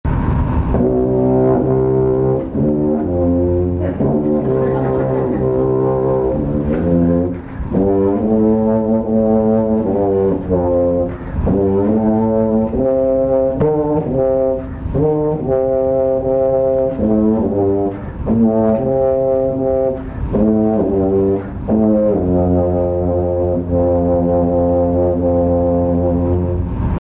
Recorded at Boston Museum of Fine Arts, April 30, 1998.
contrabass anaconda serpent in CC